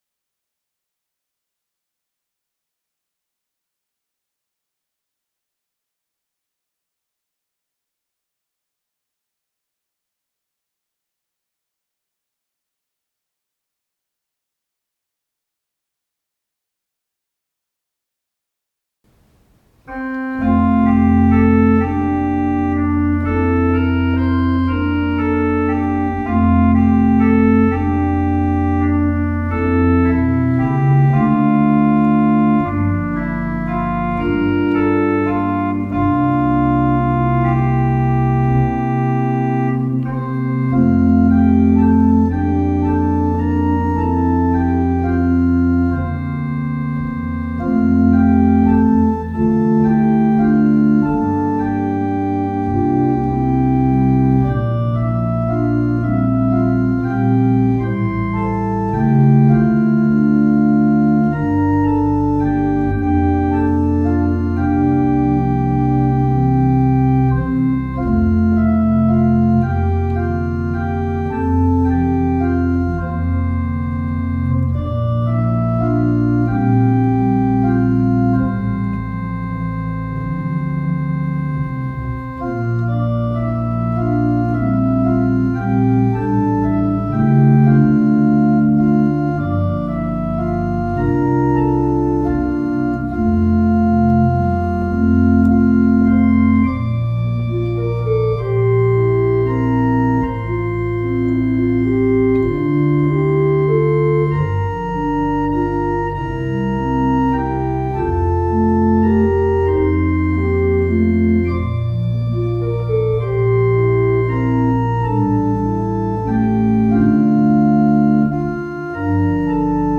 I Know That My Savior Loves Me|Organ Accompaniment or Solo
Voicing/Instrumentation: Organ/Organ Accompaniment We also have other 15 arrangements of " I Know That My Savior Loves Me ".